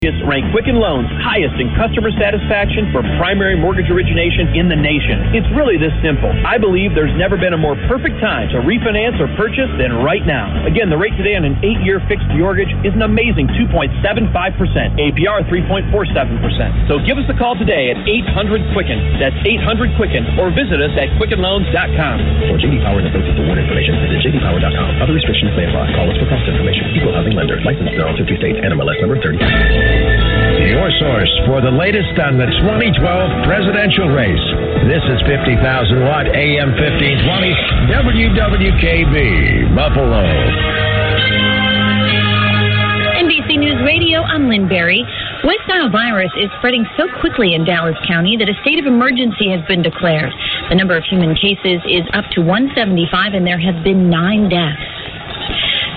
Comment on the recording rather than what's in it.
1520 WWKB Booming at 0400